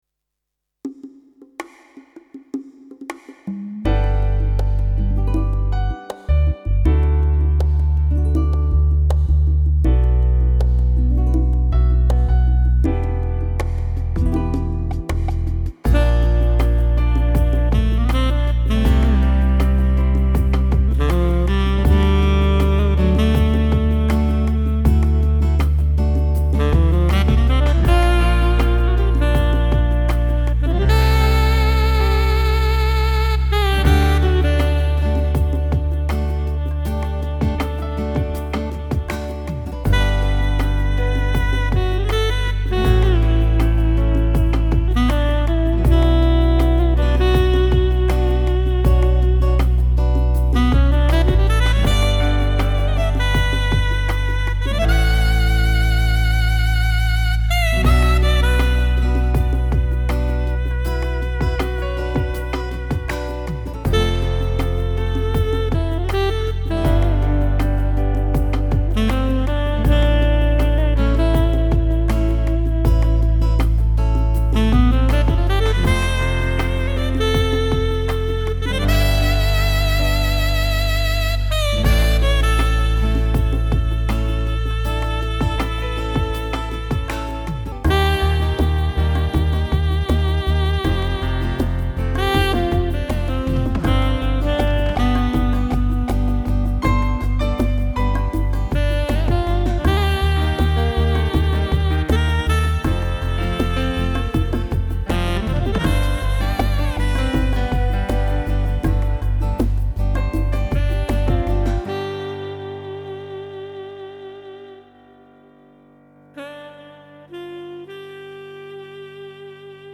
Home > Music > Jazz > Smooth > Laid Back > Traditional Jazz